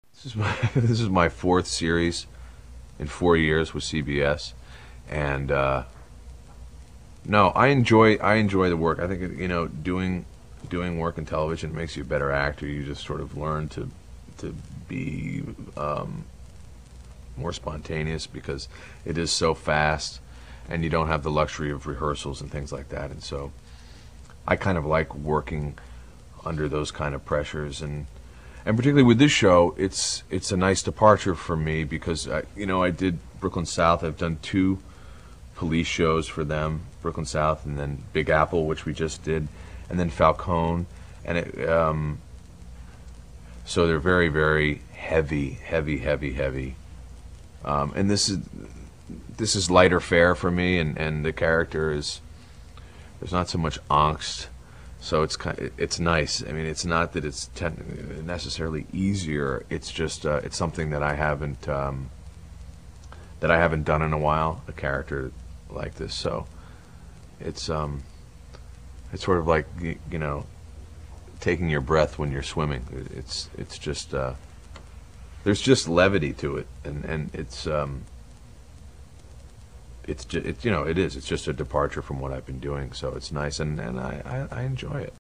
This interview, in part, appeared on the TV Guide website.